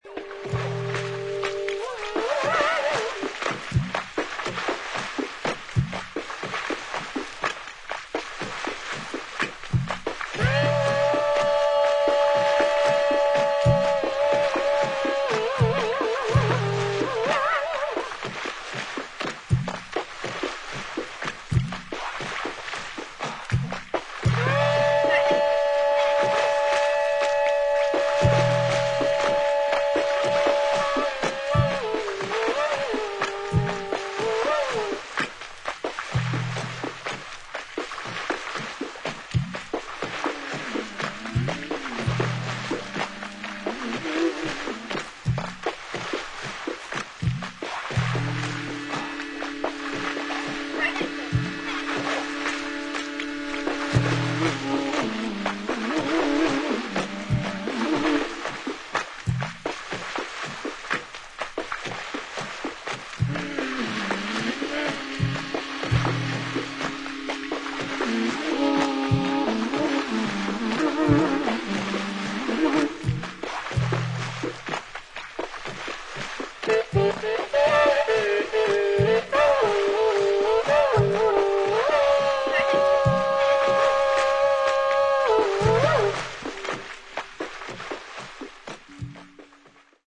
MEDIA : VG＋ ※B1にチリノイズあり